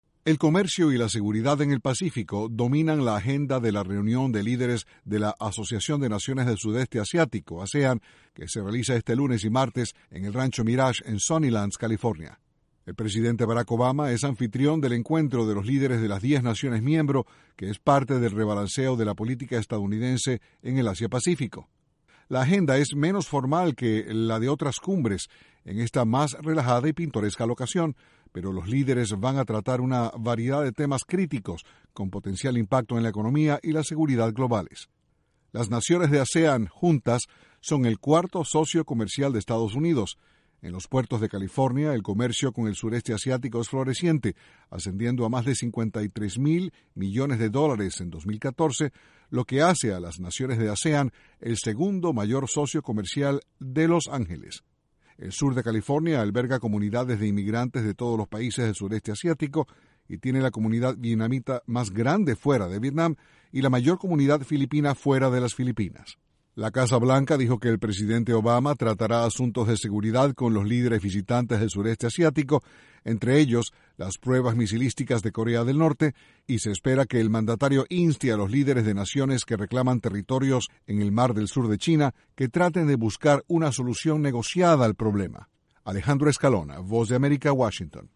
El martes concluye cumbre de ASEAN en California. Desde la Voz de América, Washington